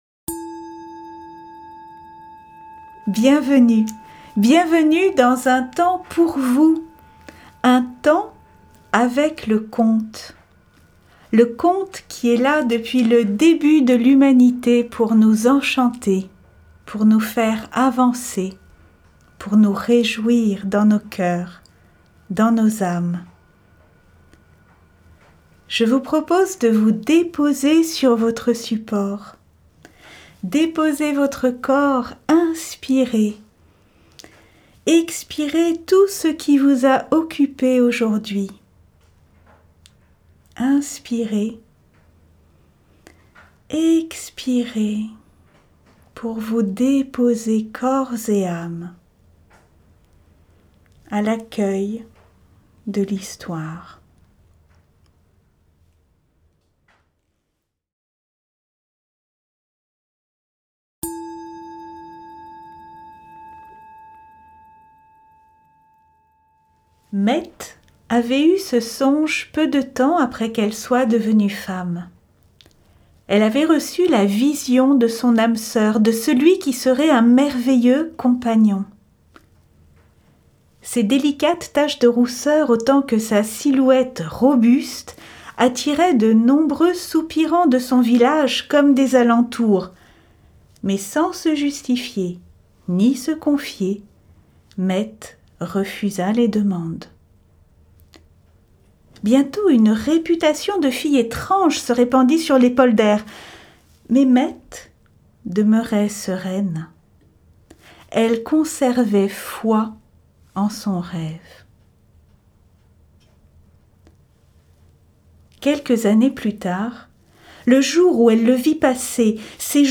un conte initiatique